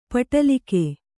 ♪ paṭalike